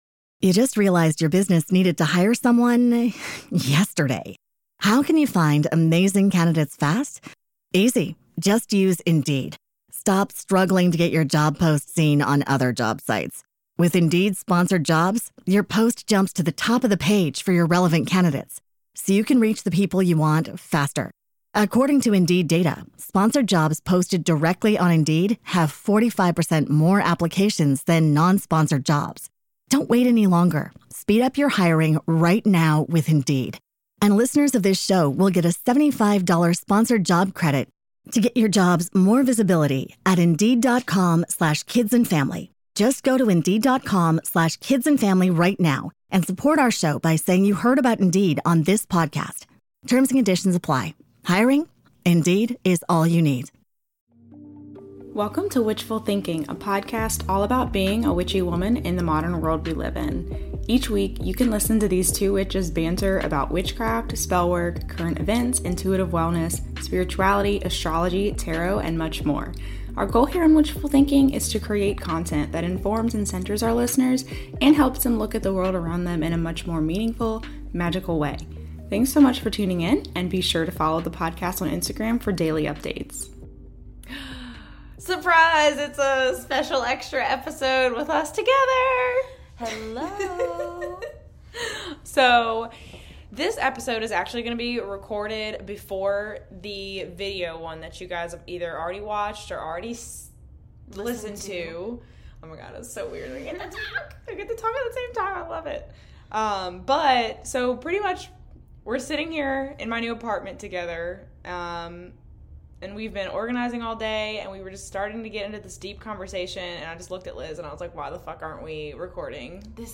SURPRISE! Another episode where we're together in person! In this episode, we're just shooting the shit at 2 am about a variety of different things, such as a future tarot deck collaboration, feeling physically strong, being single at the same time as one another, euphoric feelings, relationships, and more. Stay tuned until the end for a nice little concert, and don't forget to listen for the Easter egg!